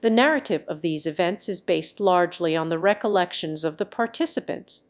Matcha-TTS - [ICASSP 2024] 🍵 Matcha-TTS: A fast TTS architecture with conditional flow matching